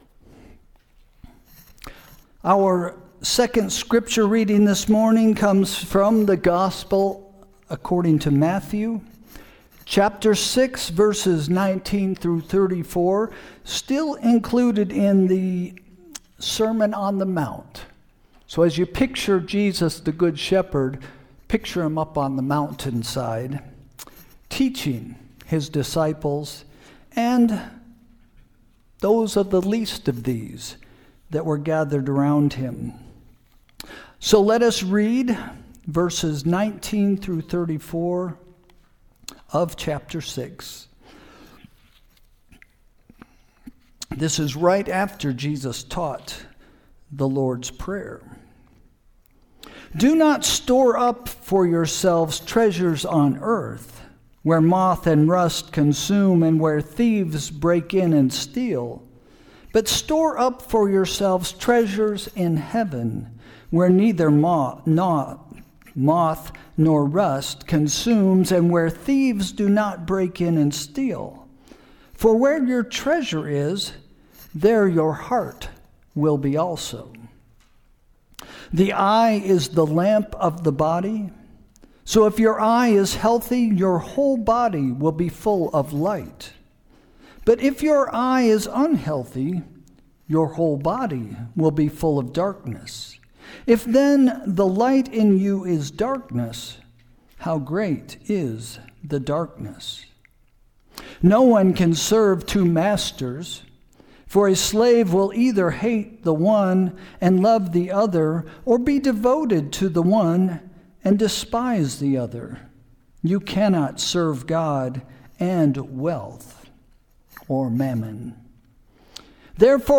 Sermon – March 15, 2026 – “Building A Good Life”